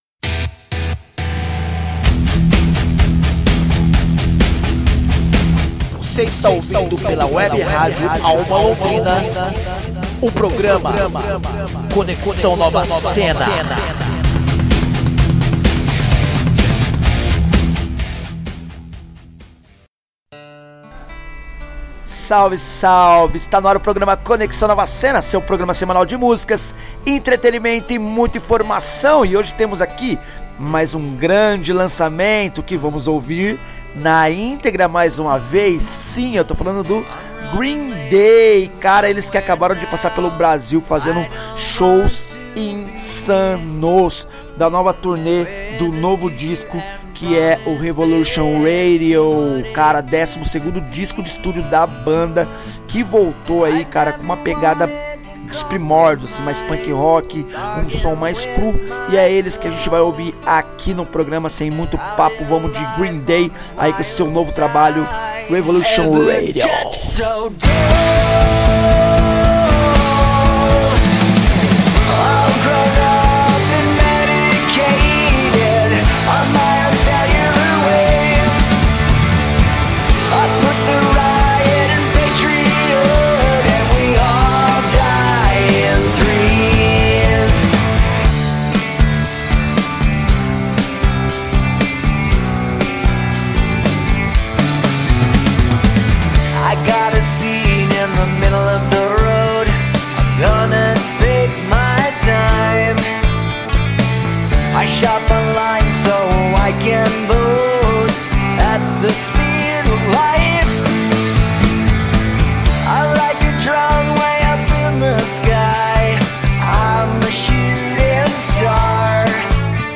pop punk rock